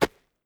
SAND.1.wav